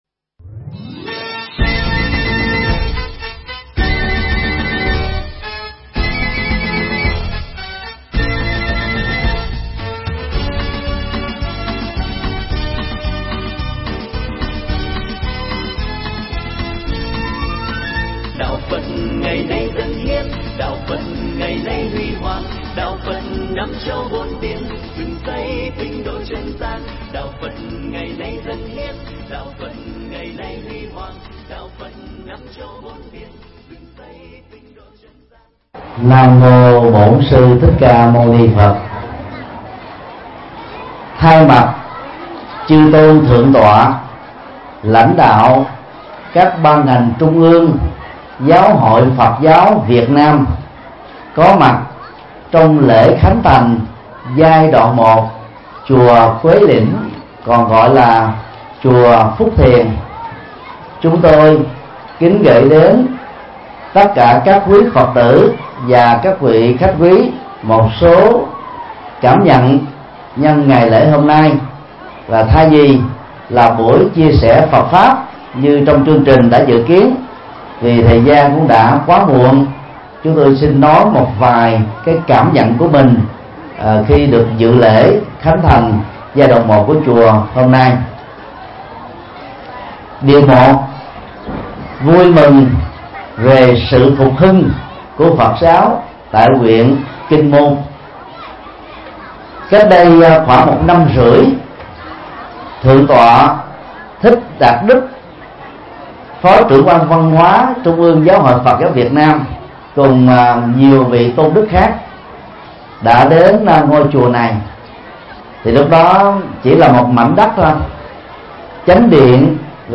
Mp3 Pháp Thoại Xây chùa làm phúc cho dân – Thầy Thích Nhật Từ Giảng tại chùa Phước Thiền, Hải Dương, ngày 13 tháng 12 năm 2015